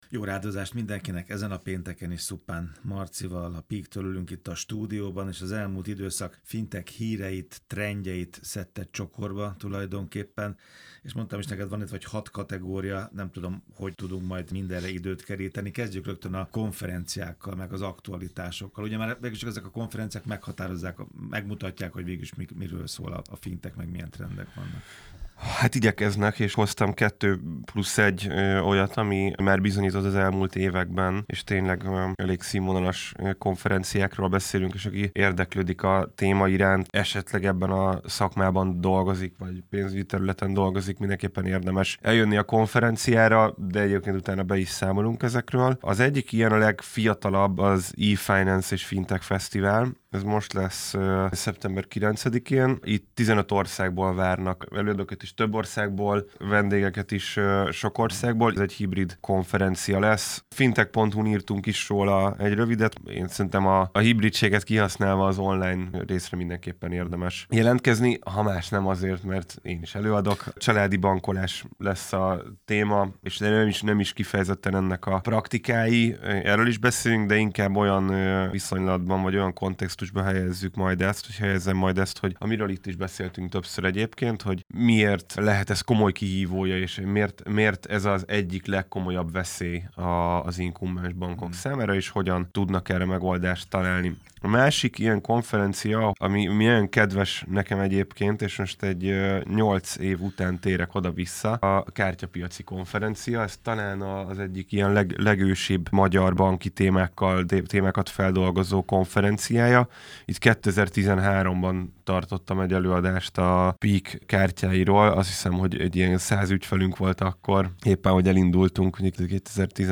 fintech szakértővel beszélgettek néhány olyan amerikai neobanki fiaskóról, amiből a világ többi részén is tanulhatunk.